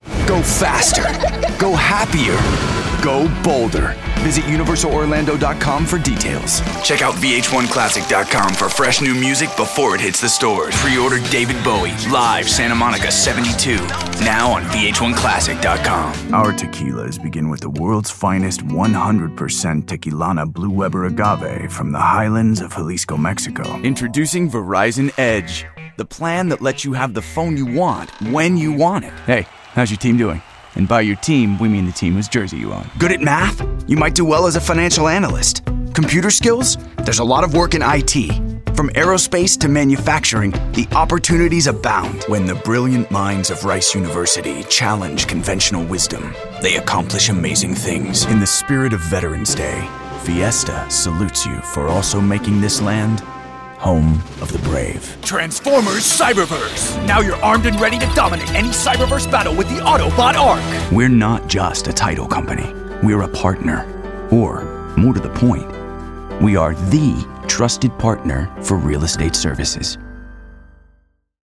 Commercial
• Commercial VO